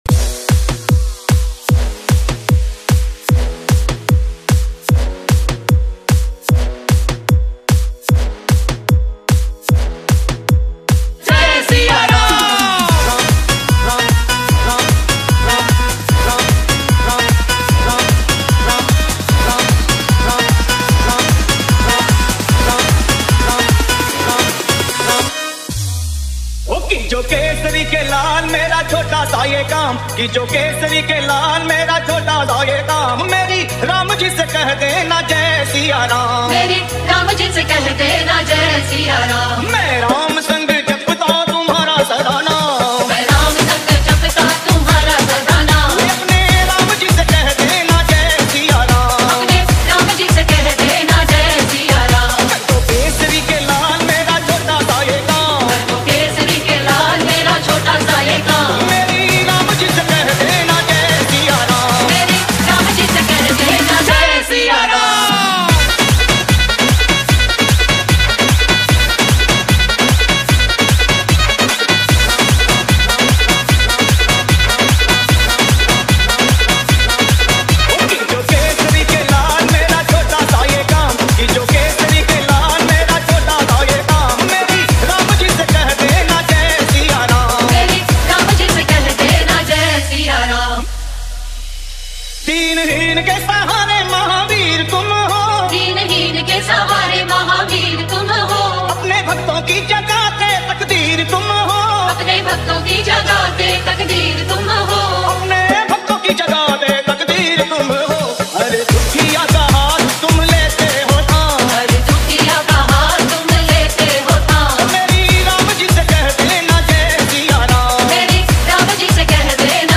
• Genre: Devotional DJ Remix / EDM
• Devotional vocals with energetic remix structure
• Heavy bass and impactful drops
• DJ-friendly intro and outro
• Perfect balance of spirituality and party vibe